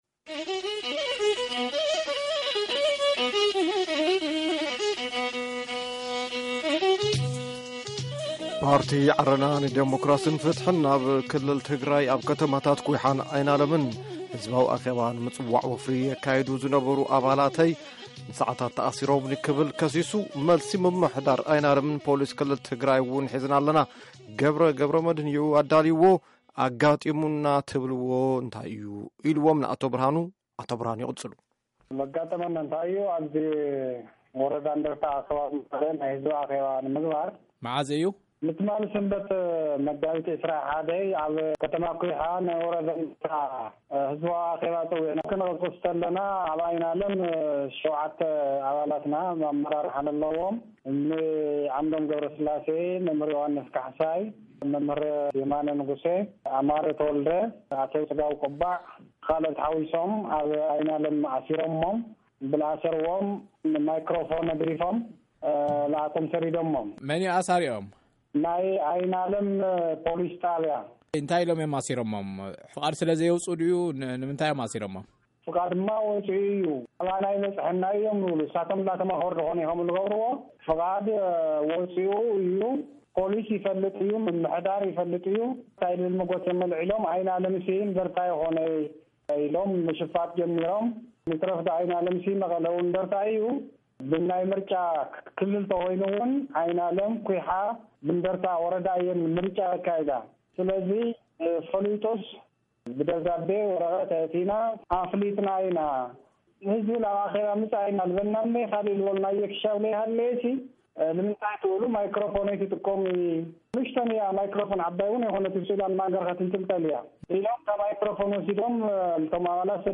ቃለ-ምልልስን መልሲ ሰበ-ስልጣንን